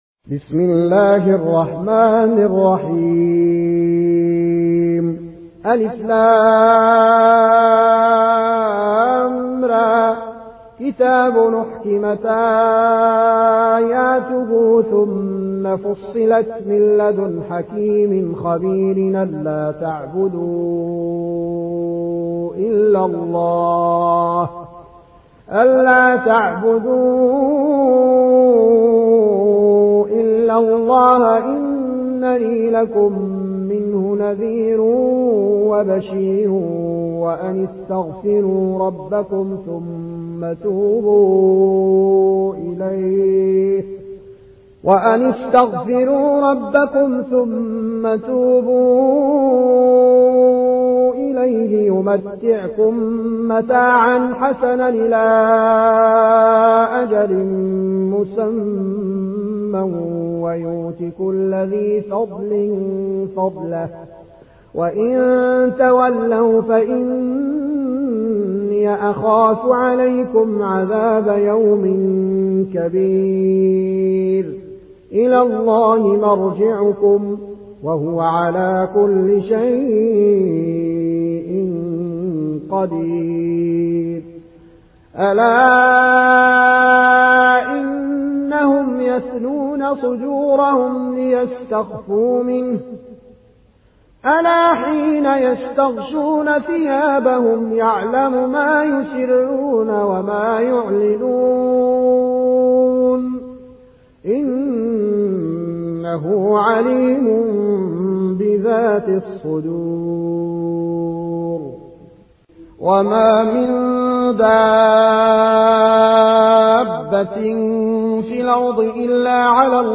روایت ورش از نافع